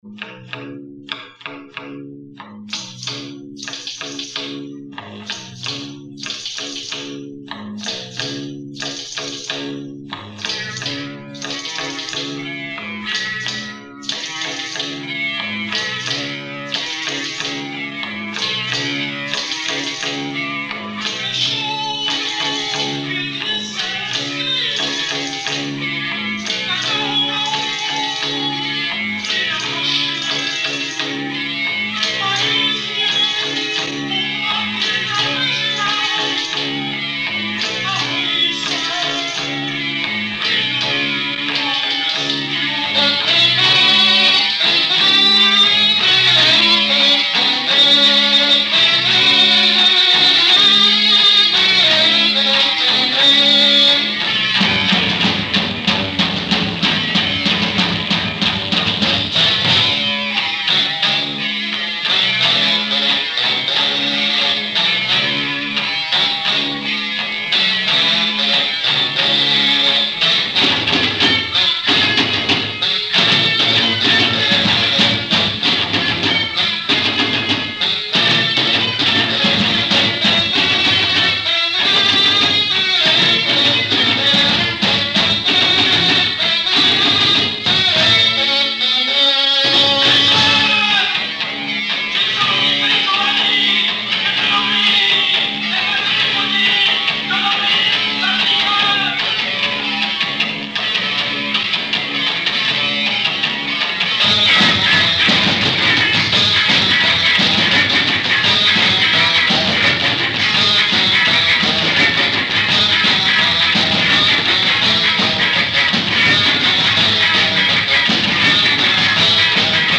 je česká avantgardní rocková kapela.